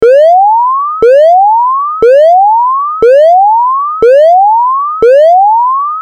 This system does have a different sound than the previous system.